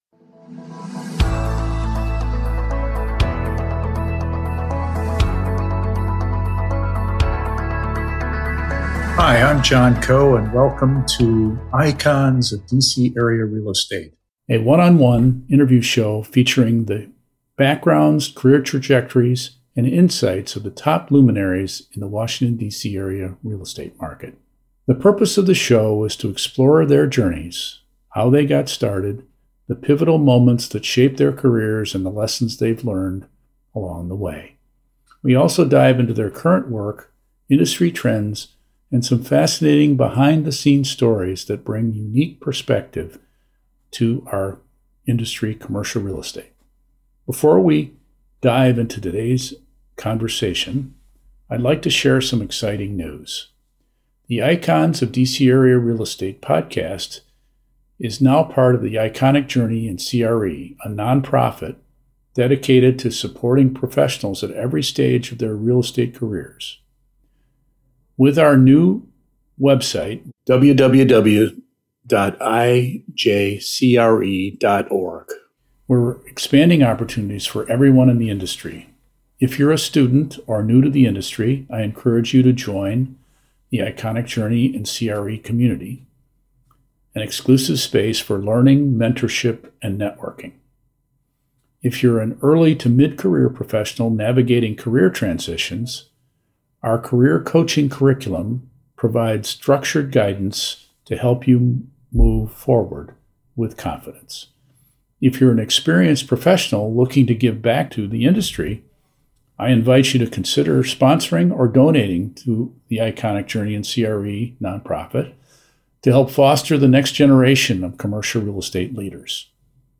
(00:00) Introduction to second interview.